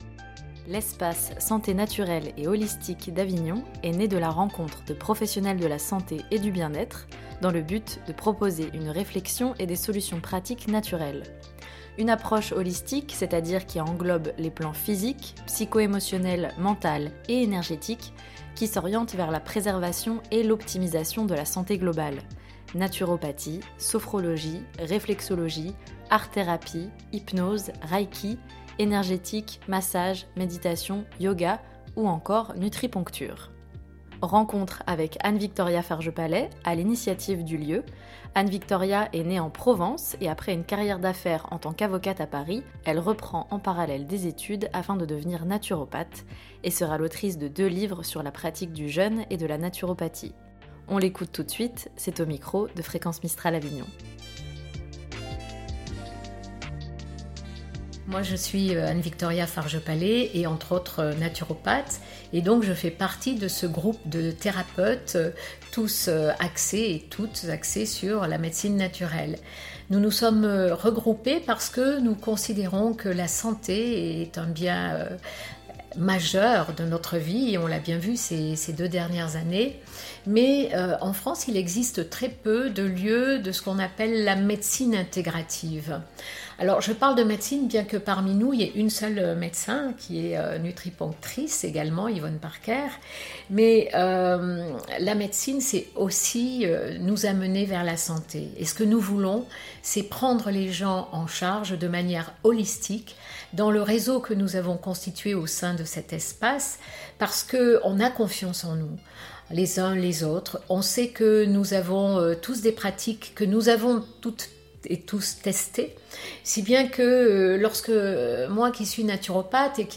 On l’écoute tout de suite c’est au micro, de Fréquence Mistral Avignon.